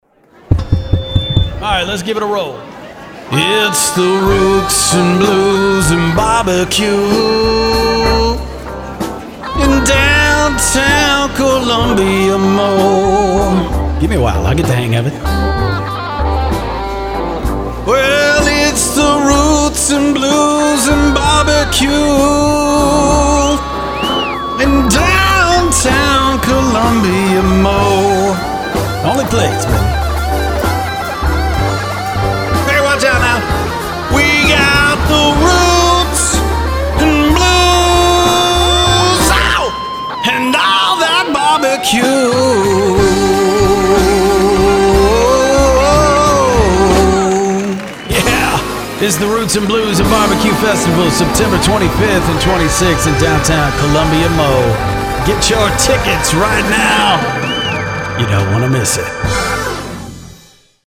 Male
English (North American)
Yng Adult (18-29), Adult (30-50)
Main Demo